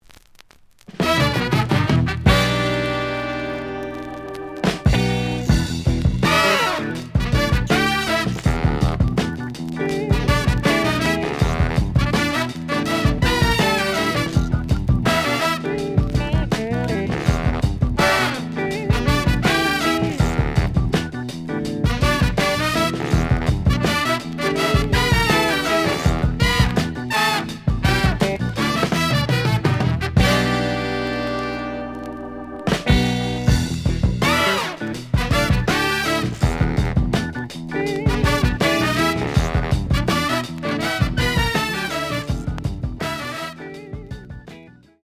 試聴は実際のレコードから録音しています。
●Format: 7 inch
●Genre: Funk, 70's Funk